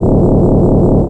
gunidle2.wav